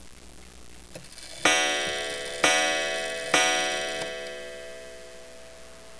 Waterbury "Doric" Shelf Clock This was a pretty neat, and fairly inexpensive grab, off eBay.